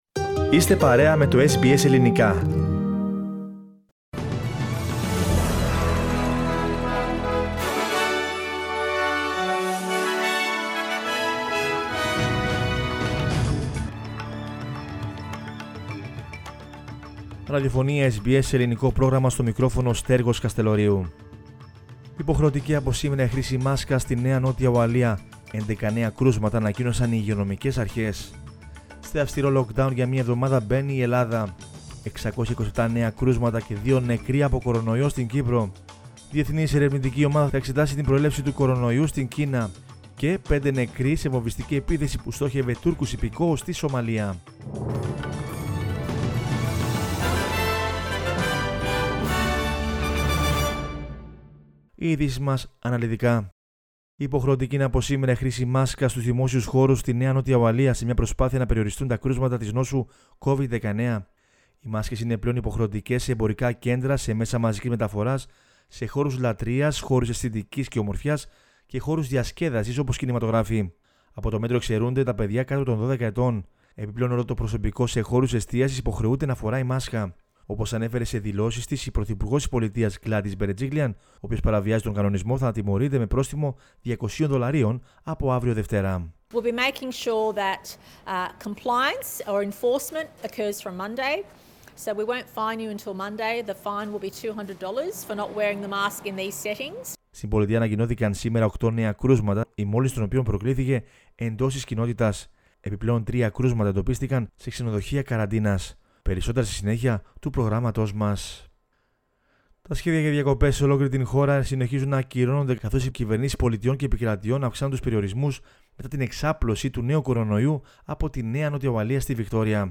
News in Greek from Australia, Greece, Cyprus and the world is the news bulletin of Sunday 3 January 2021.